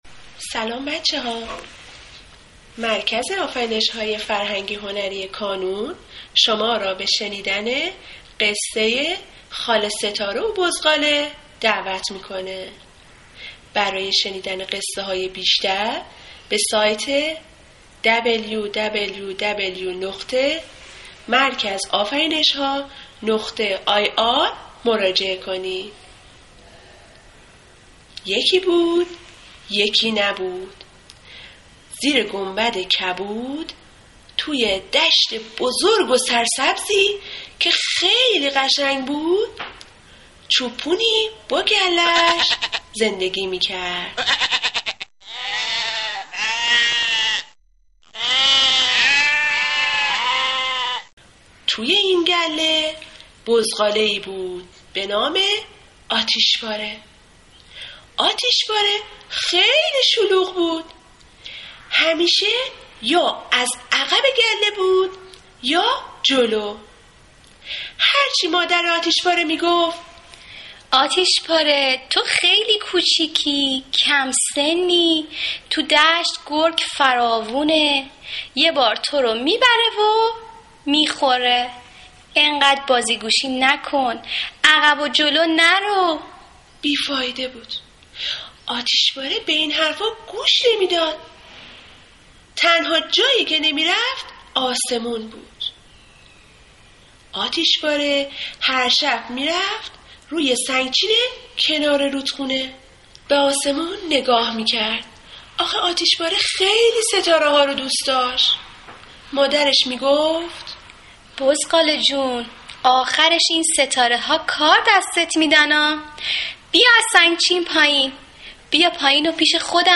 قصه کودکانه صوتی شب + داستان کوتاه کودکانه برای پیش دبستانی و دبستانی ها